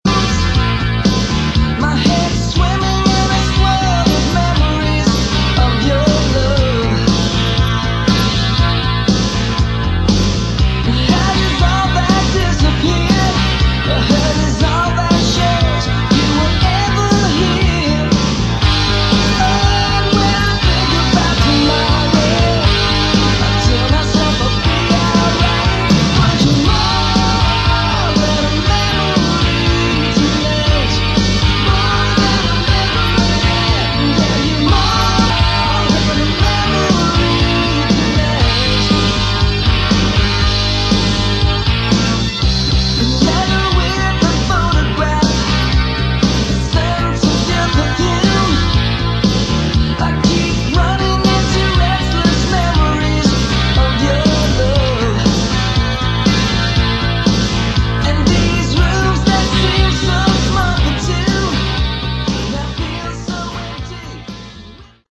Category: AOR / Melodic Rock
Guitar, Lead Vocals
Bass, Vocals
Drums
Keyboards